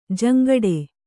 ♪ jaŋgaḍe